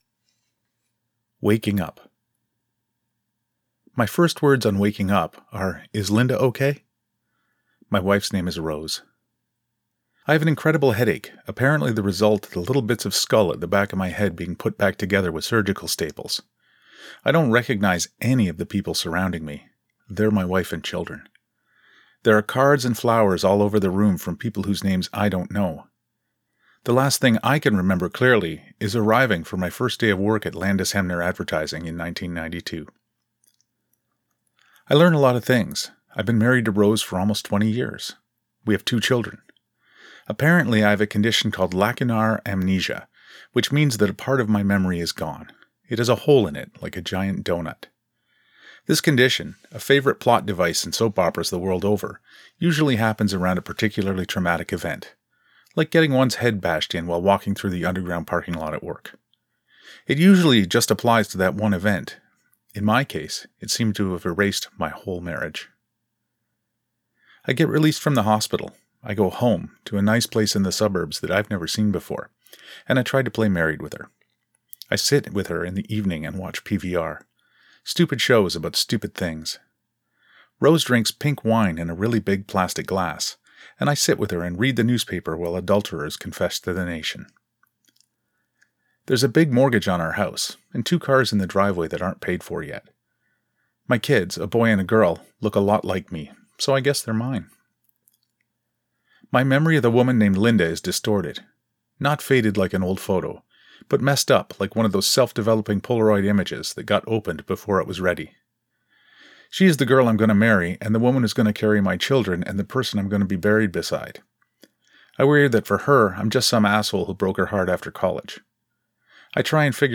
While setting up my home studio to start teaching students online, I decided to record a couple of stories that I’ve written.